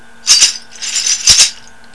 植物繊維の篭に、ボトルの王冠が入ってます。よく通る、かなり（大きい物ほど）にぎやかな音です。
バスケットラットル音